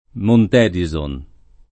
Montedison [ mont $ di @ on ]